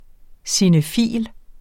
Udtale [ sinəˈfiˀl ]